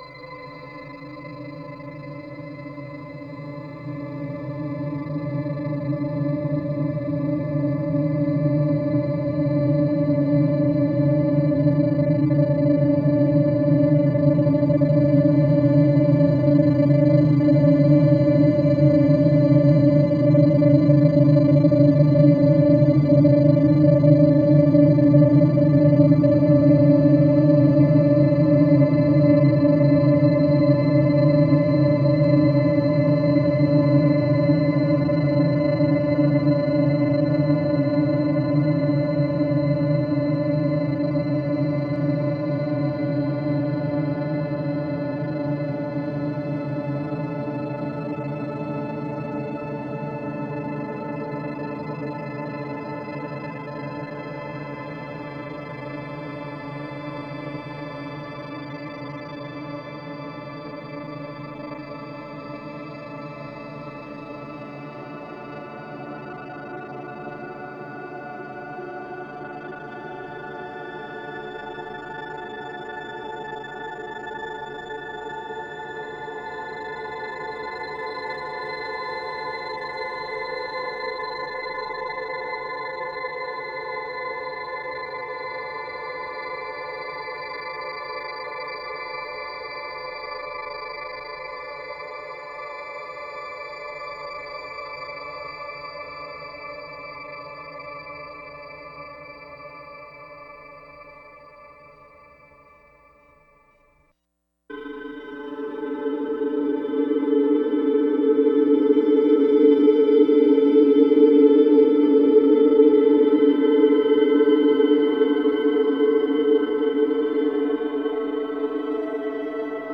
Source: Resonated harmonic gliss on G (4:02-6:17)
Processing: granulated, amp. correlated at max. 50:1 + 1 oct. down